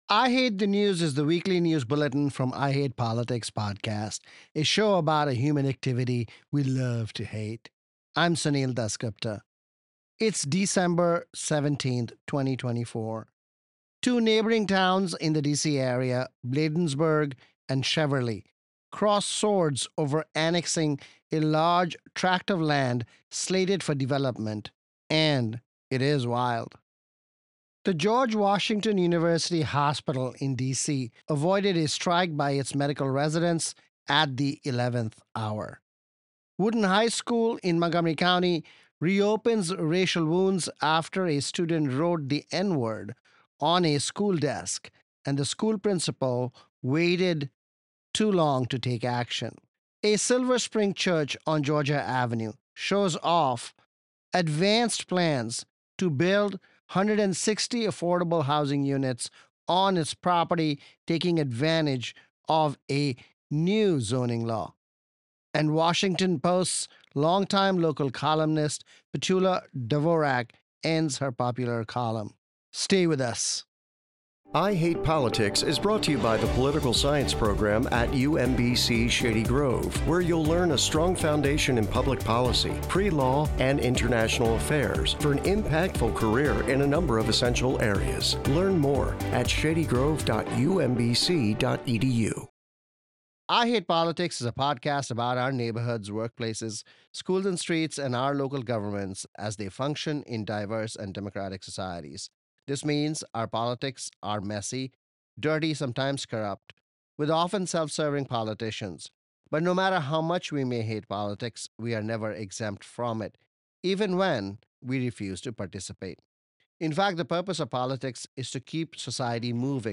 The weekly news analysis from I Hate Politics: DC area towns Bladensburg and Cheverly fight over annexing a site ready for redevelopment. George Washington University Hospital narrowly avoids a medical resident strike. Silver Spring United Methodist Church unveils plans to build affordable housing on church grounds.